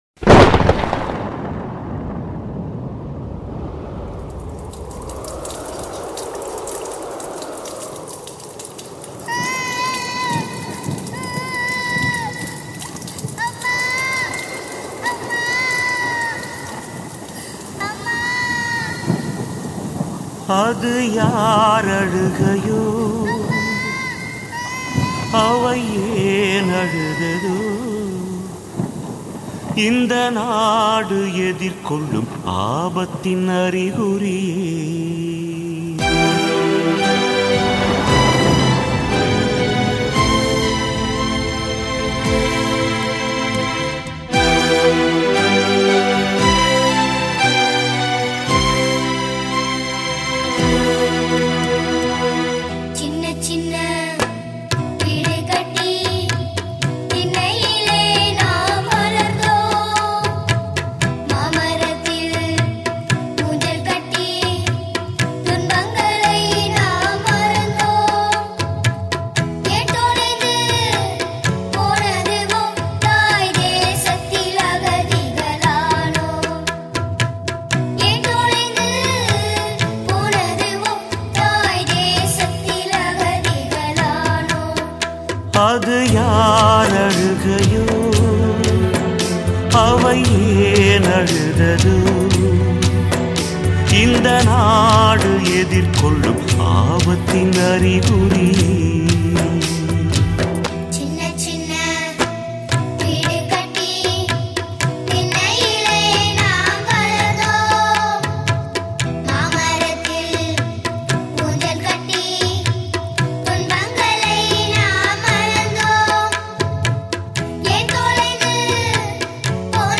A poignant call and response style song